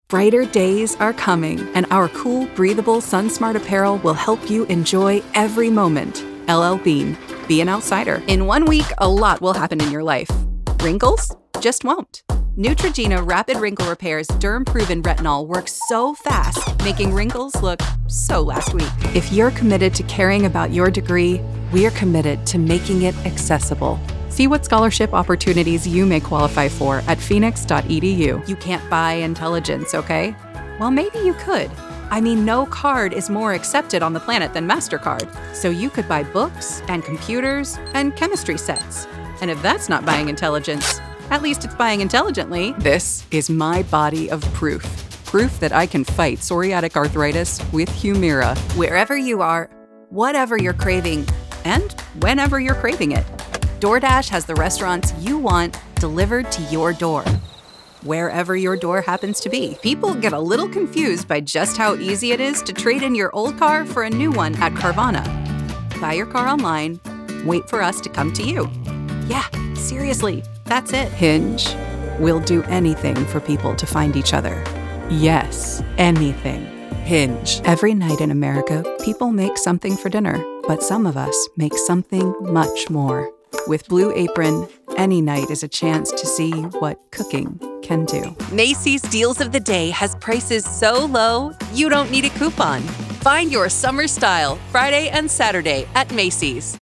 Female Voice Over, Dan Wachs Talent Agency.
Sassy, Dramatic, Conversational.
Commercial